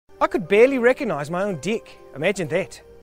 all-three-new-zealand-deck-ads-hd-quality-1.mp3